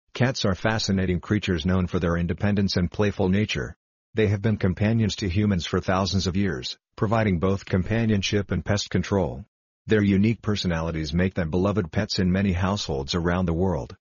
Classic Text-to-Speech
You can hear classic text-to-speech in action by playing the narrations below.
Standard Voice
standard_tts_voice.mp3